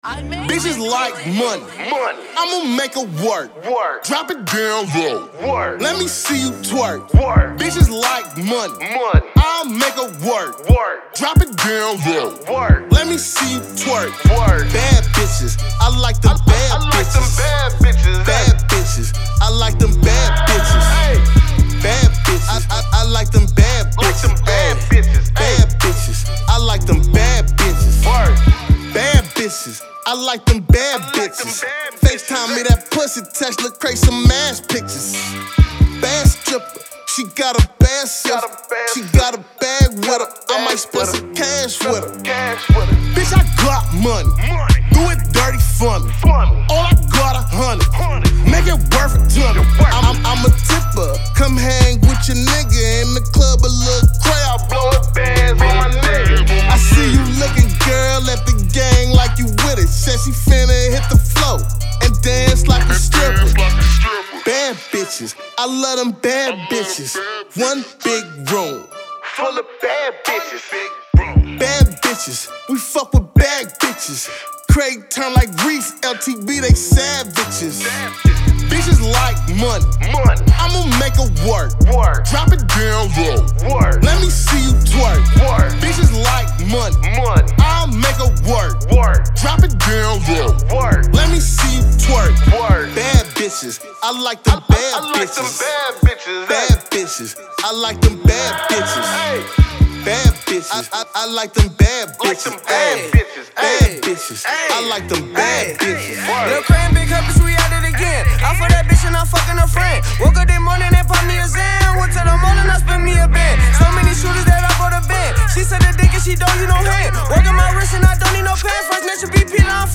Hiphop
TWERK Anthem!